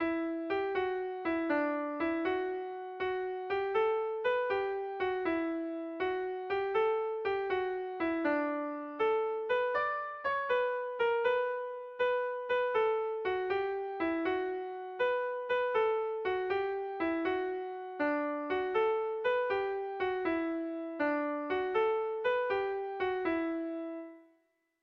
Erlijiozkoa
Barrutiaren ACTO DE NOCHEBUENA antzerkiko bertsoa doinu gregorianoan
ABDE